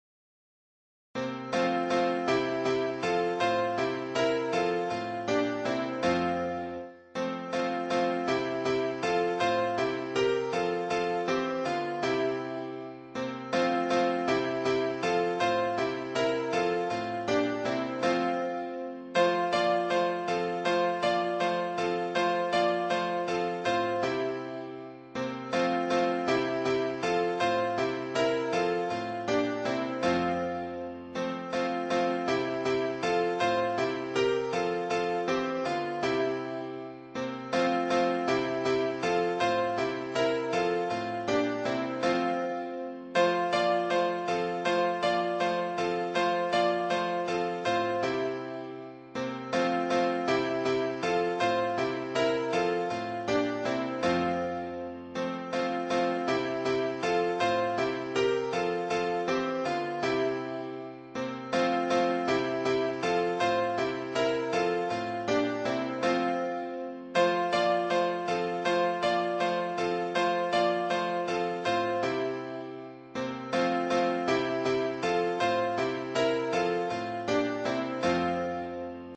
伴奏
原唱